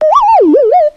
Media:sprout_hurt_vo_04.ogg Dying sound.
受伤的声音